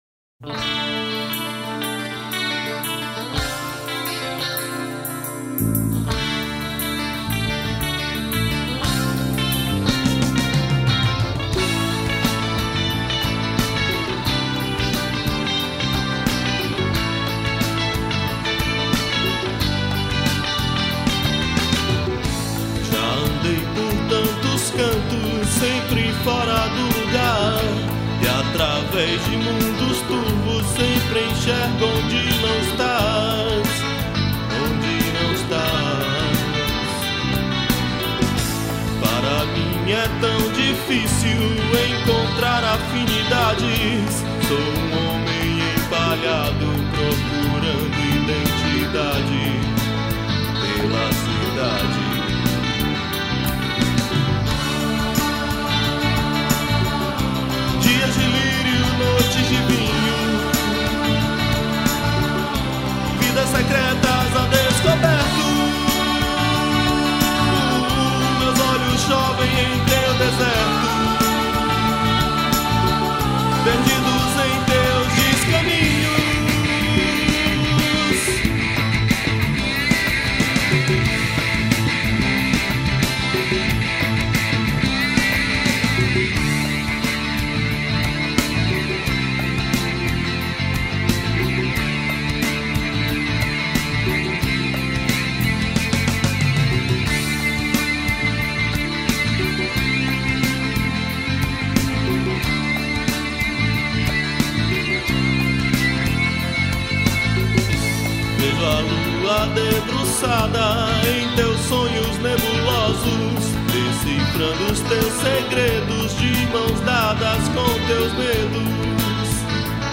1036   04:16:00   Faixa:     Rock Nacional
Guitarra
Teclados
Percussão
Vocal
Baixo Elétrico 6